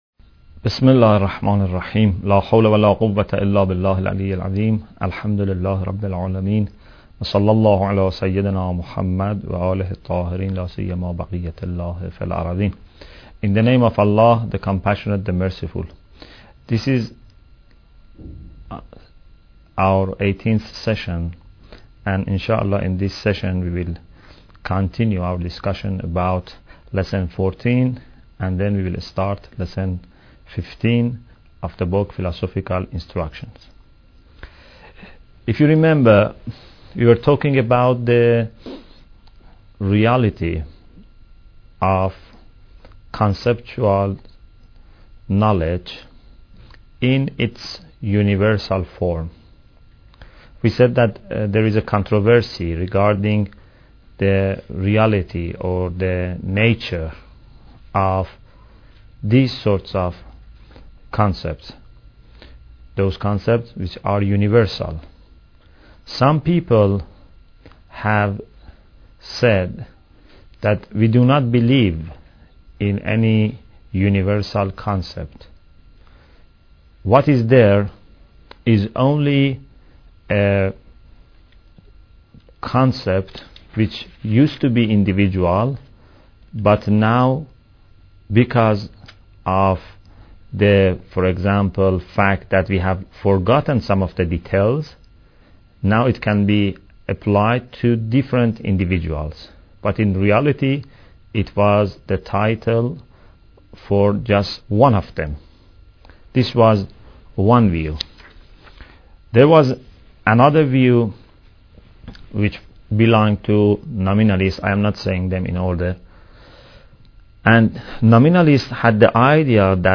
Bidayat Al Hikmah Lecture 18